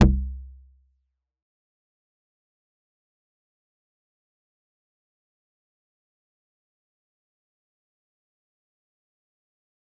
G_Kalimba-F1-f.wav